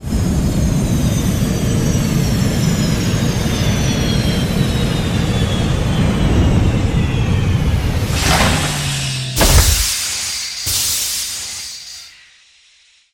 landing1.wav